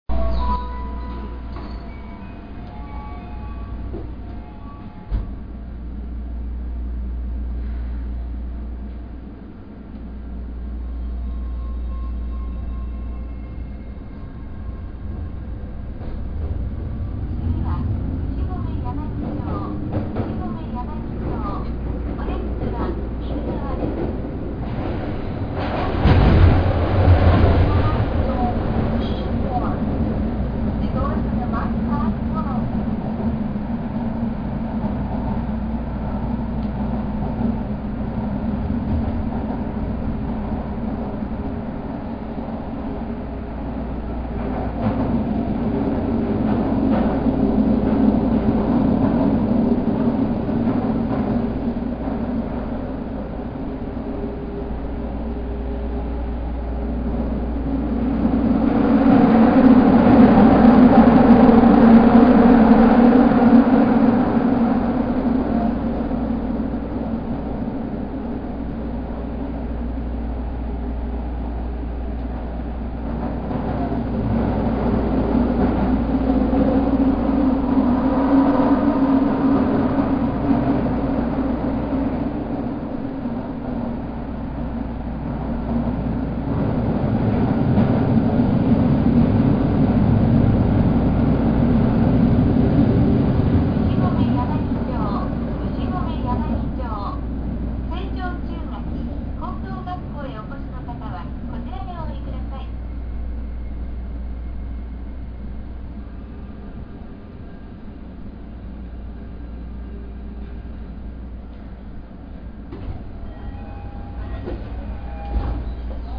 ・12-000形（３次車〜）走行音
【大江戸線】牛込神楽坂→牛込柳町
初期の日立IGBTなのですが、リニアモーター地下鉄故に最初の転調の後に野太い音が聞こえるのが大きな特徴。車両の音も大きい上に後期開業の地下鉄故に線形で無理をしているので全体的に走行音は喧しくなってしまいます。
kagurazaka-yanagicho.mp3